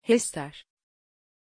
Pronunția numelui Hester
pronunciation-hester-tr.mp3